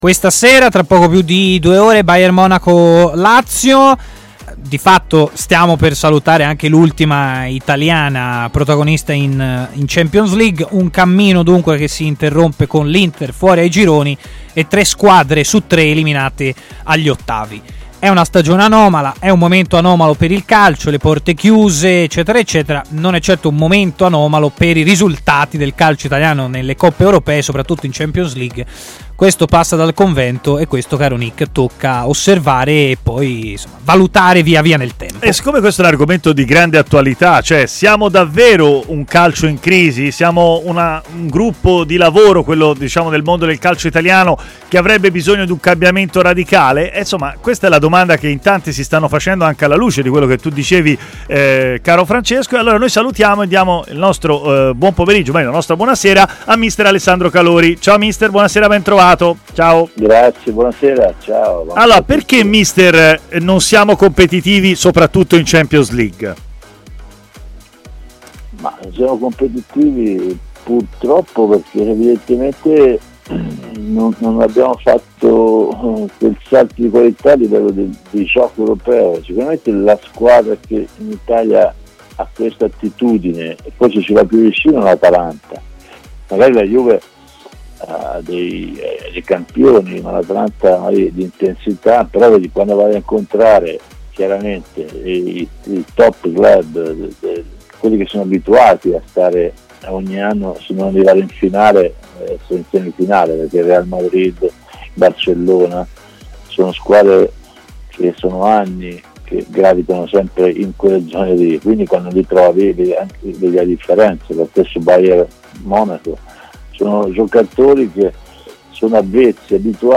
è intervenuto in diretta